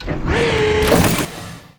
transferclose.wav